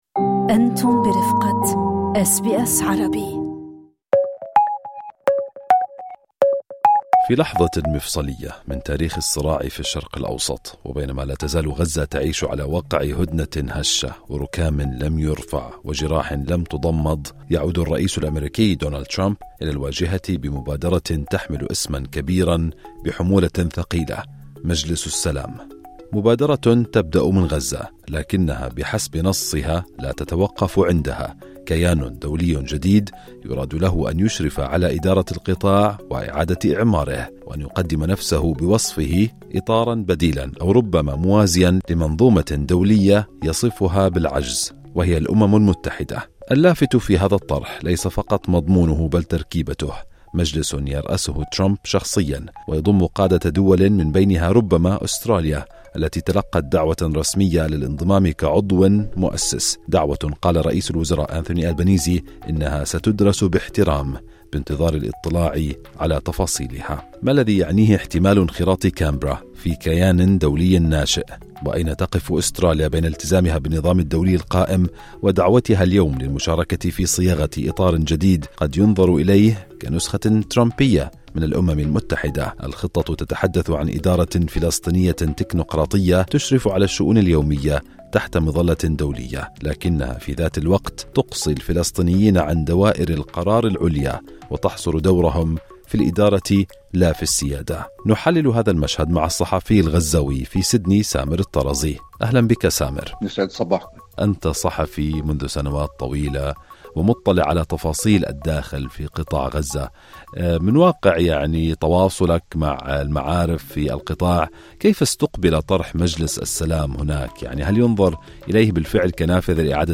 أمل من تحت الركام أم وصاية جديدة؟ صحافي فلسطيني يقرأ بين سطور "مجلس السلام"